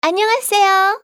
archer_f_voc_sticker_01_b.mp3